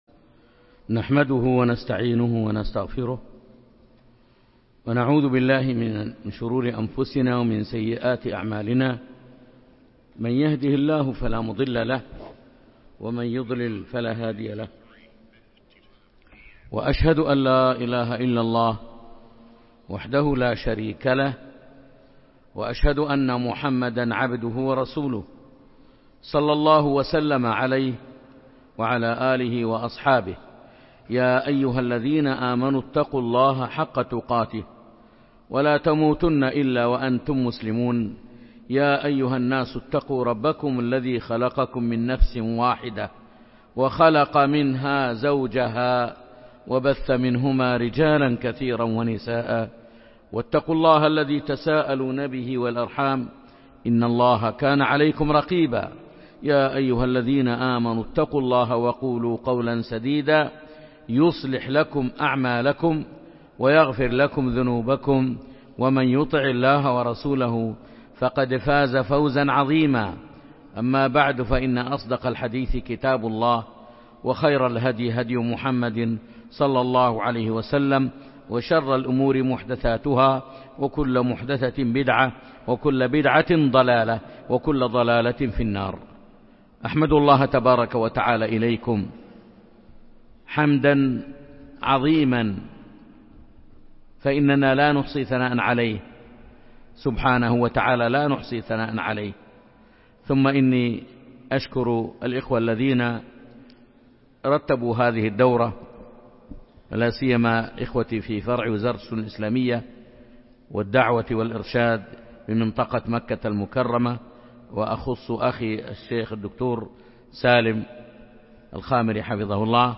جامع عائشة الراجحي بمكة المكرمة